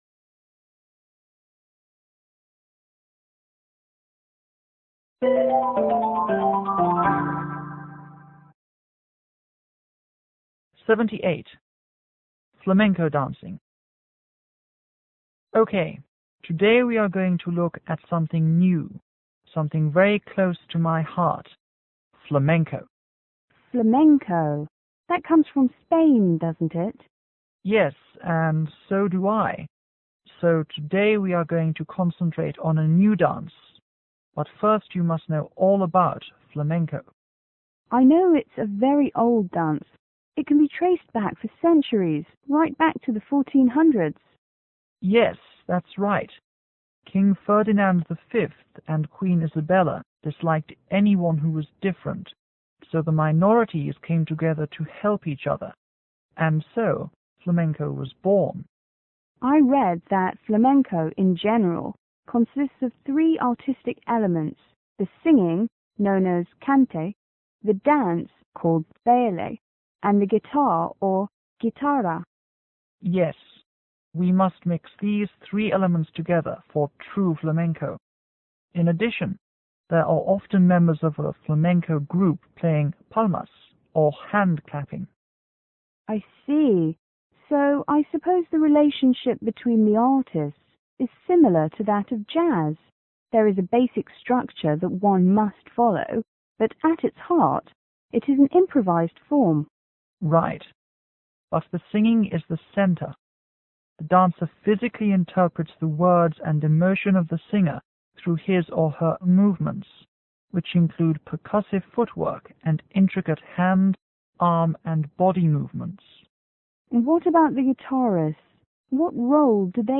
T：Dance teacher        S:Student